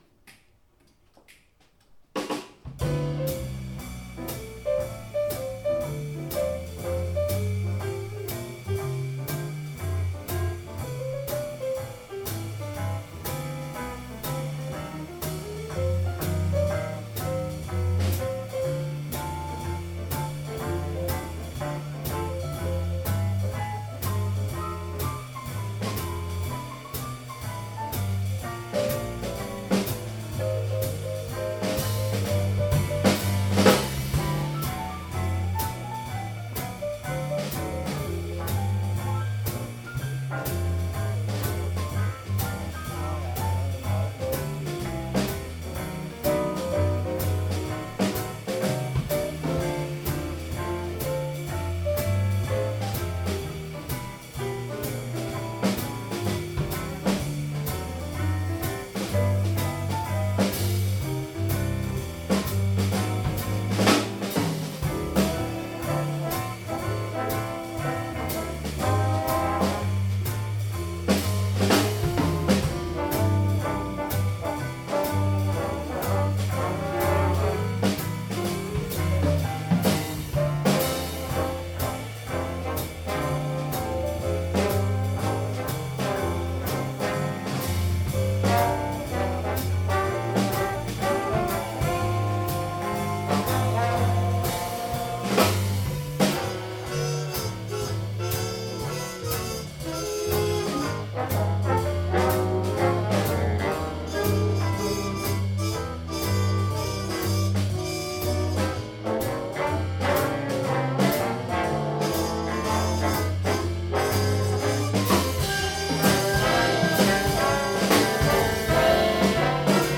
Galleri - Jazz in a Blue Funky Space oktober 2014
- Hayburner Big Band 26. oktober 2014